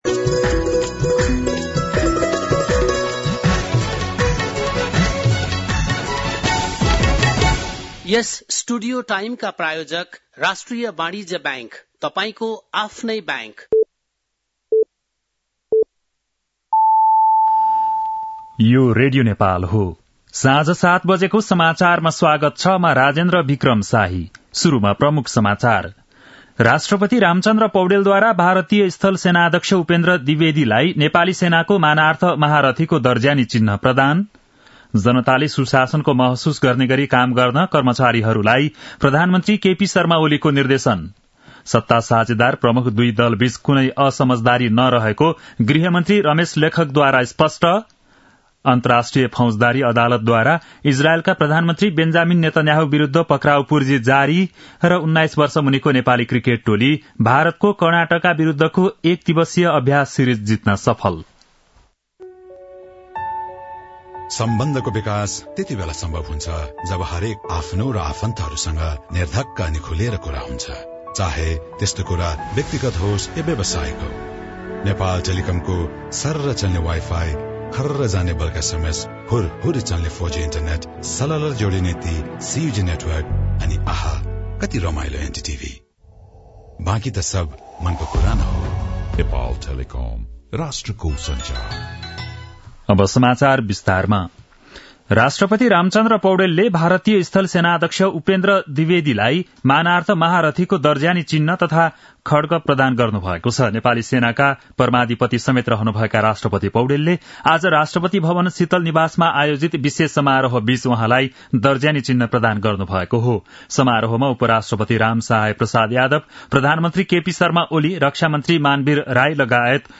बेलुकी ७ बजेको नेपाली समाचार : ७ मंसिर , २०८१
7-pm-nepali-news-8-6.mp3